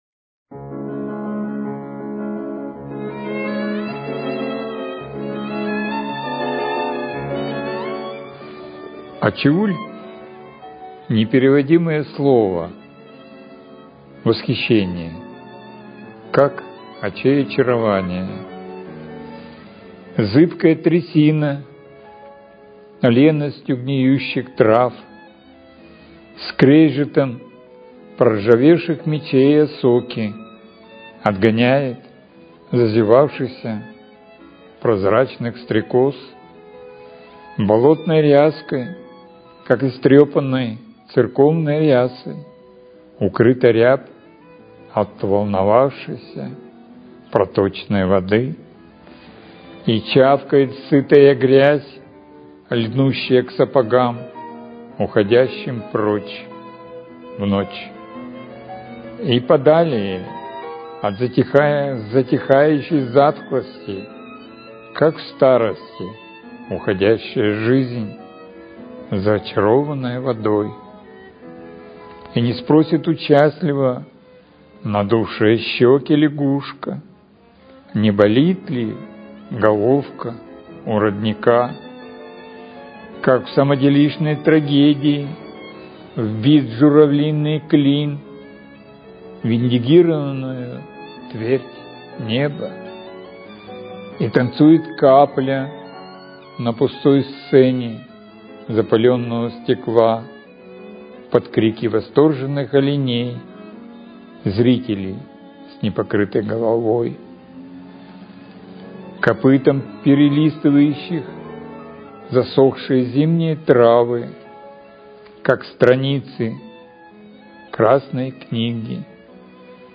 читает свои стихи